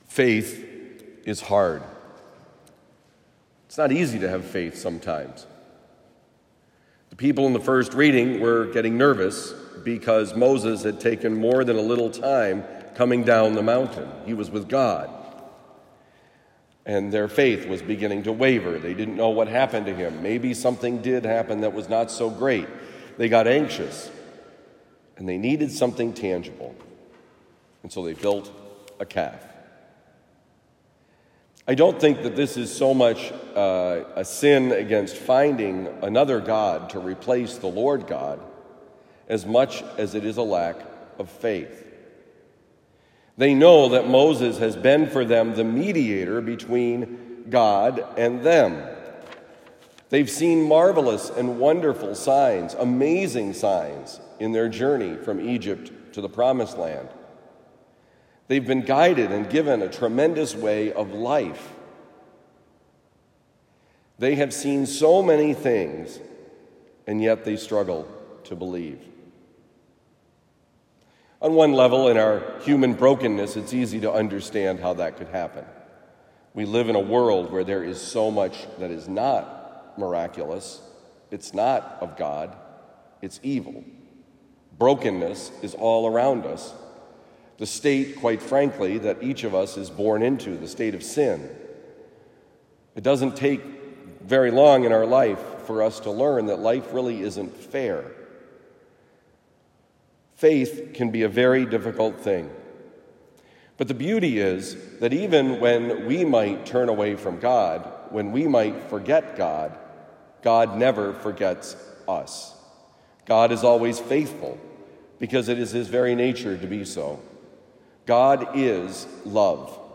Faith is Hard: Homily for Thursday, March 23, 2023
Given at Christian Brothers College High School, Town and Country, Missouri.